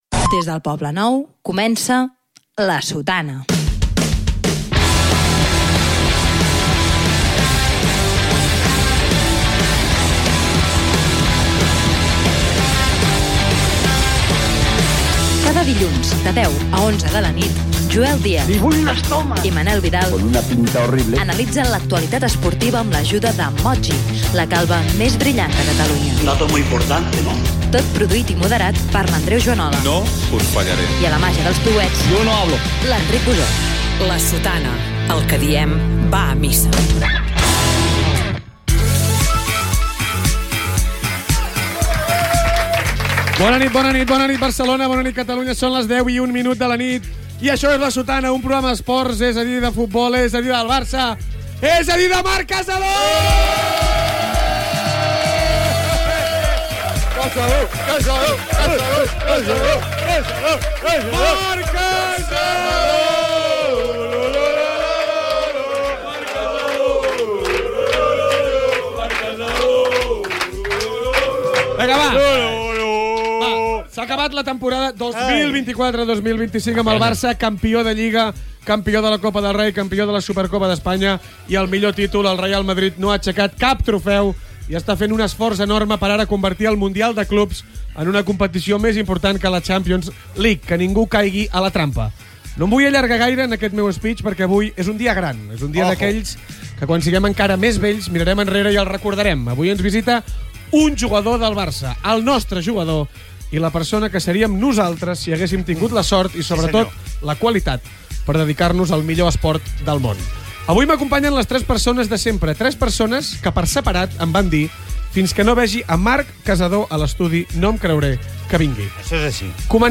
Careta del programa, hora, presentació, "La mamada", indicatiu i presència al programa del jugador del Futbol Club Barcelona Marc Casadó Gènere radiofònic Esportiu